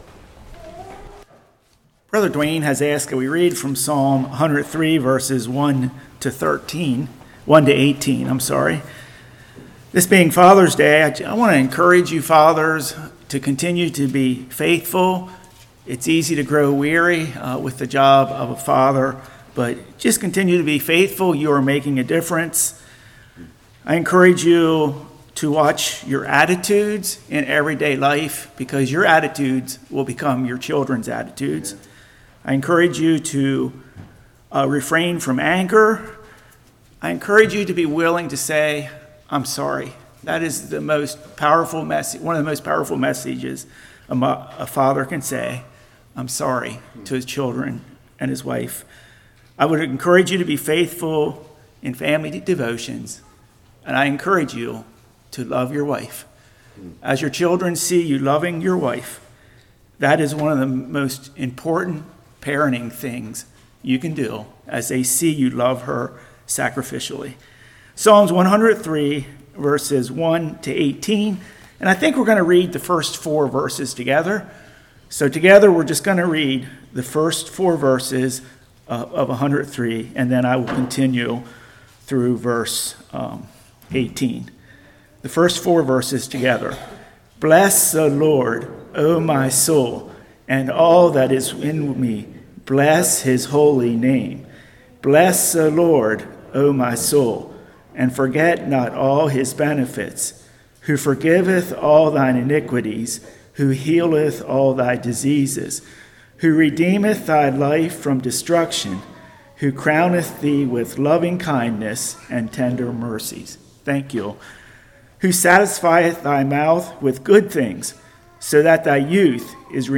Psalm 103:1-18 Service Type: Morning I. Be Grateful II.